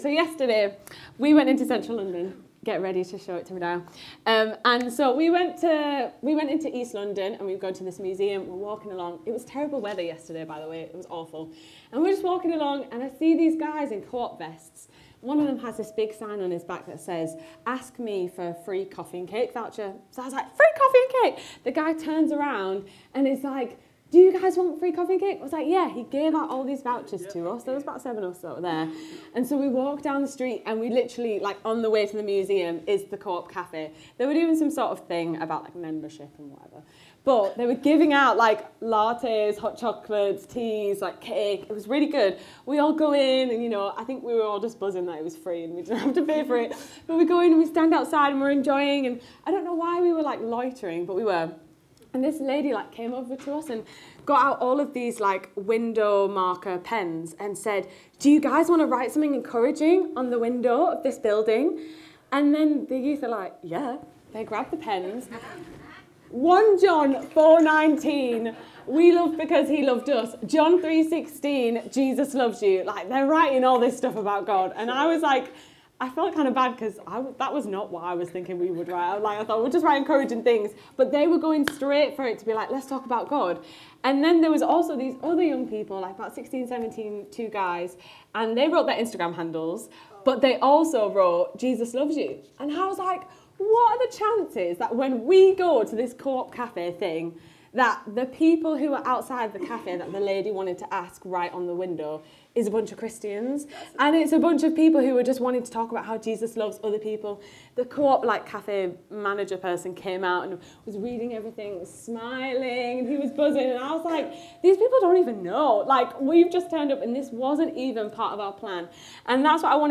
Download Perfectionism | Sermons at Trinity Church